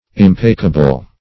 Search Result for " impacable" : The Collaborative International Dictionary of English v.0.48: Impacable \Im*pa"ca*ble\, a. [L. pref. im- not + pacare to quiet.